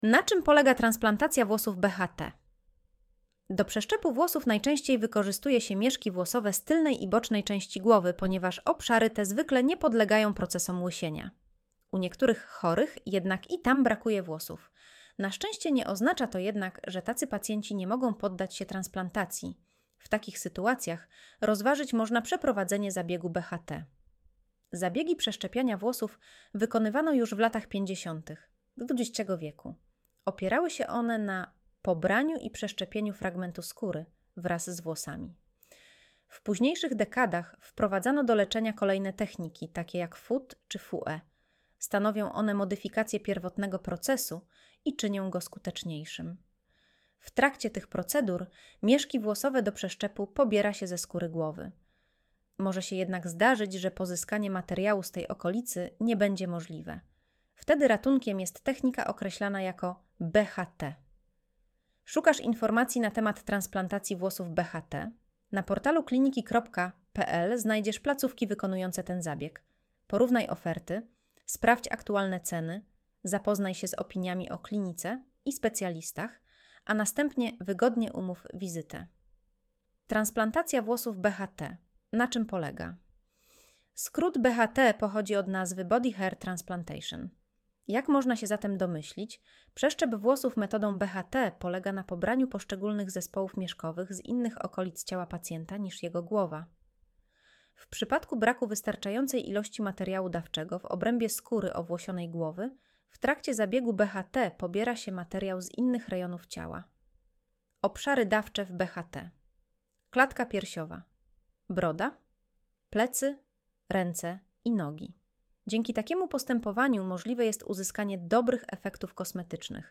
Słuchaj artykułu Audio wygenerowane przez AI, może zawierać błędy